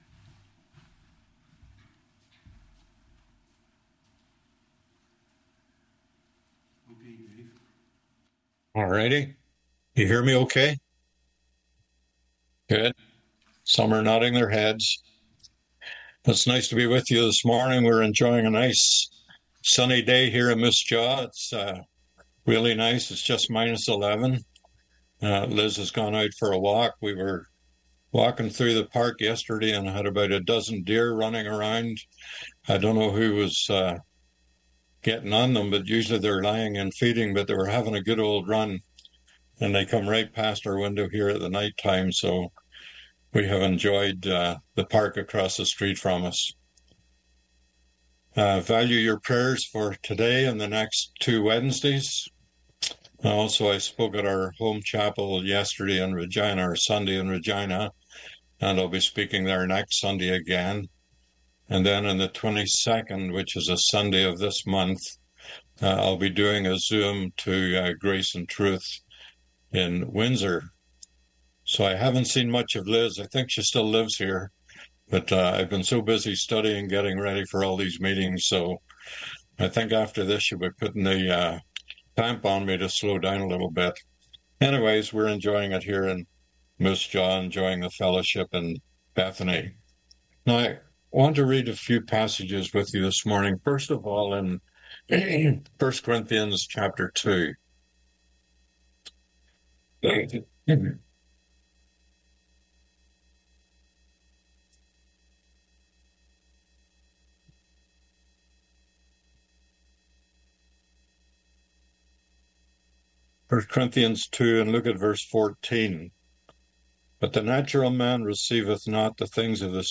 Passage: 1 Corinthians 2:14-3:3, John 13:1-15, 21-30 Service Type: Mid week « Ruth Chapter 2 Does Your Speech Say You are a Christian?